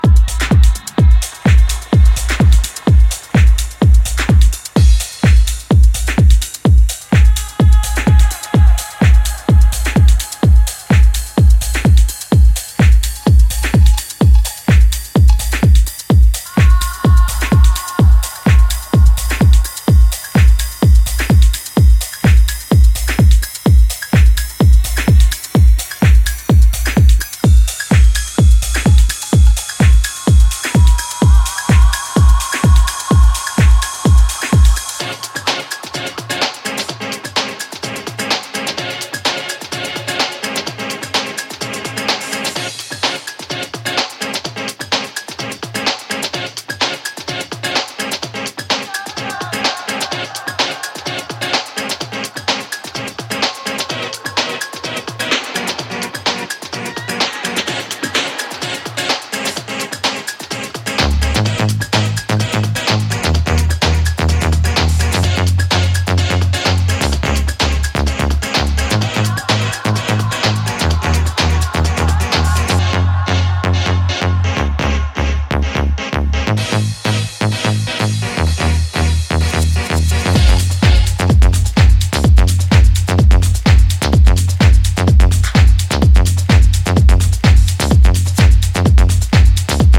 straight up house jams, hints of techno, nods to broken beat